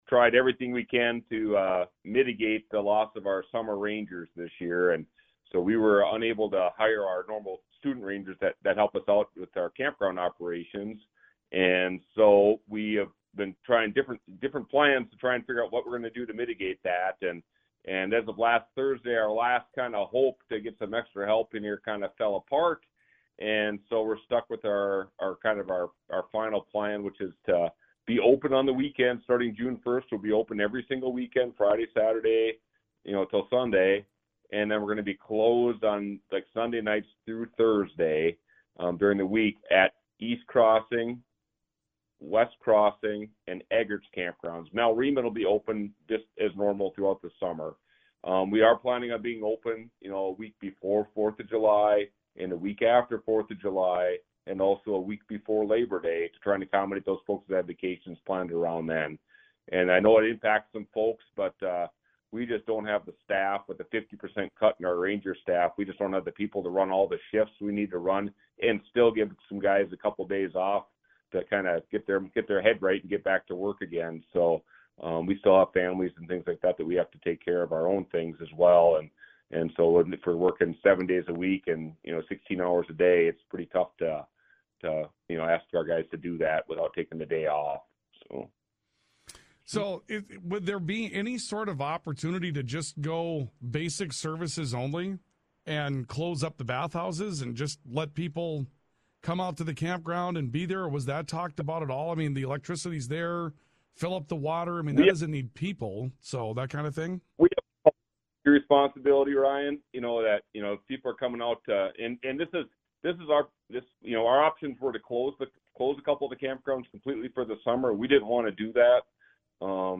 The full conversation